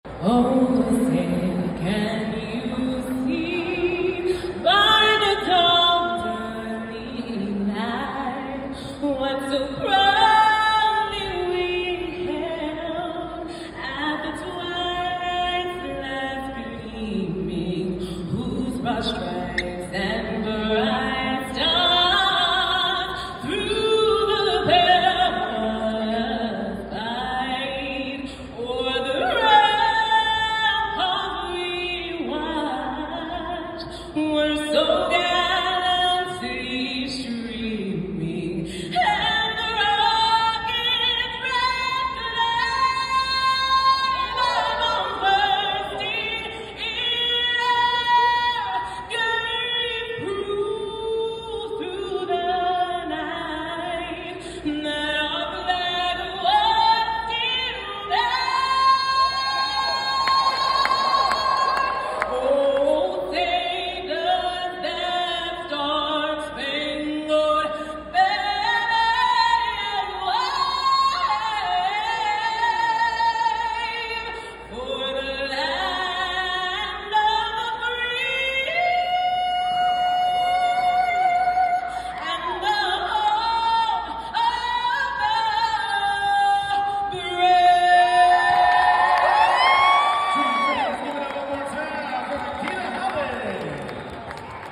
Powerhouse Vocalist: Incredible range and a bold, soulful voice.